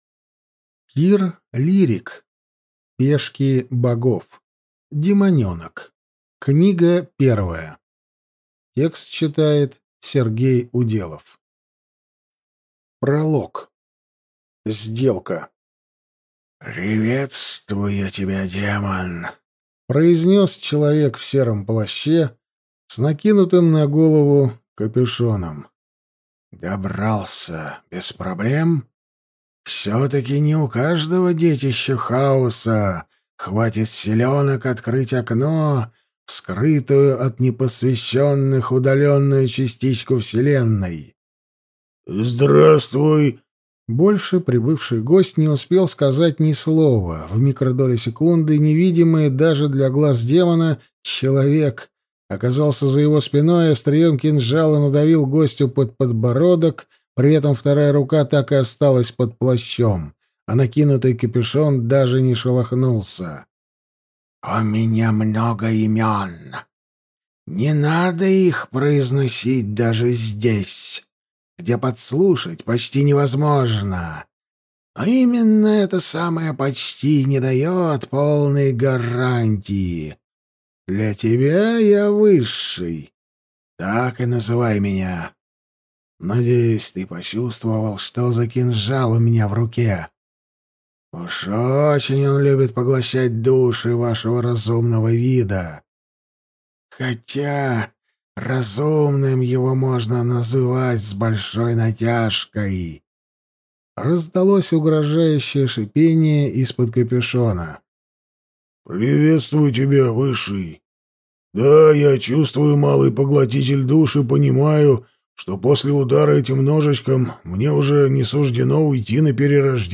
Аудиокнига Пешки богов. Демонёнок | Библиотека аудиокниг